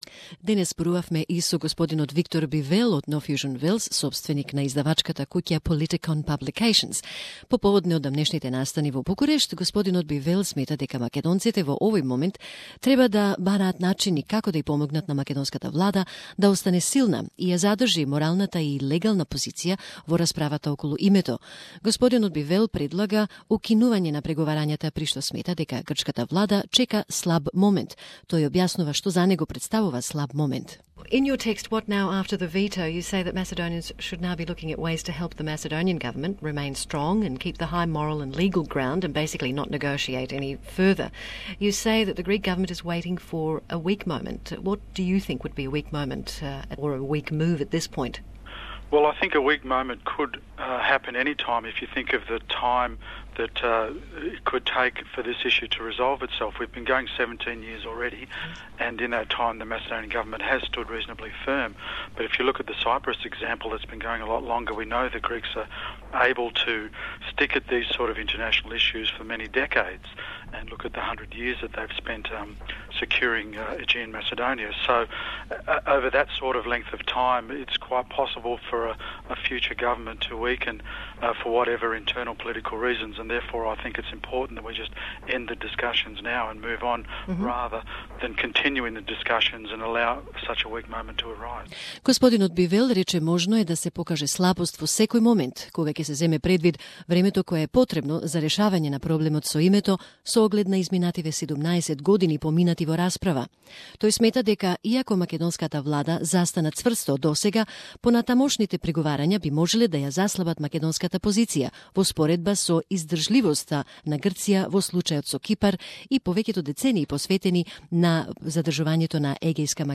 Radio Interview
The interview is in English with Macedonian interpretation.